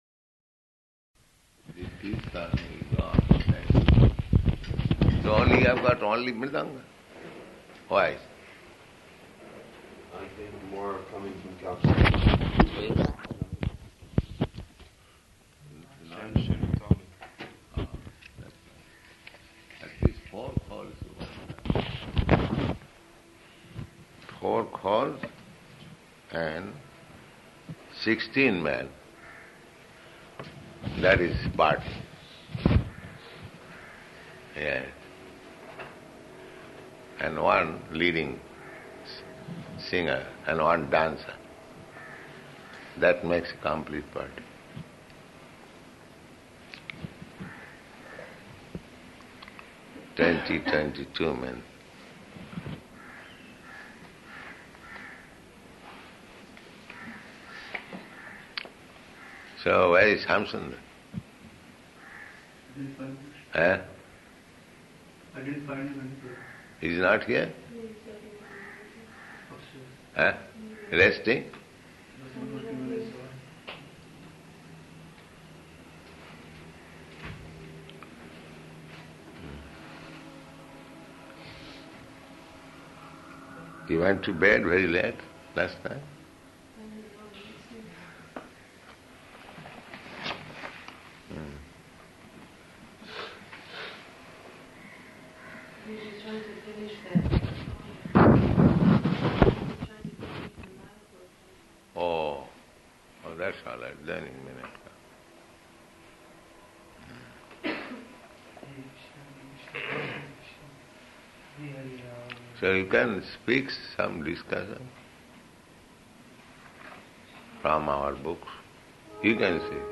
Room Conversation with Mayor